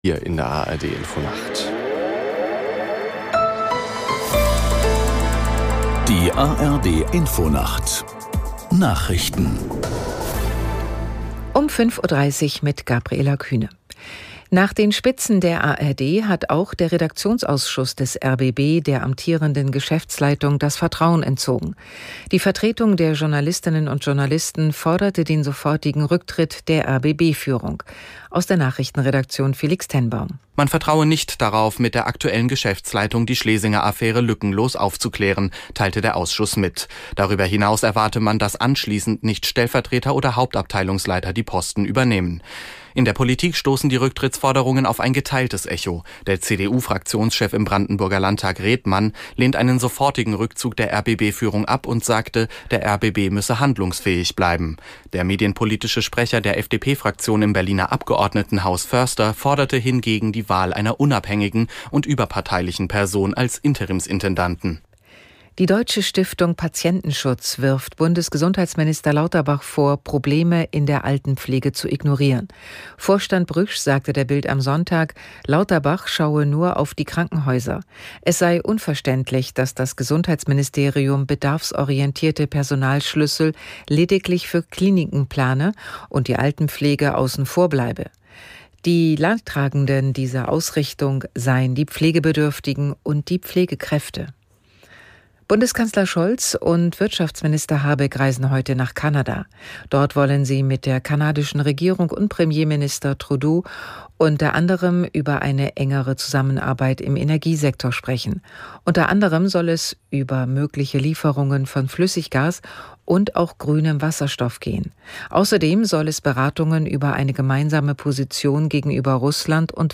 Nachrichten - 21.08.2022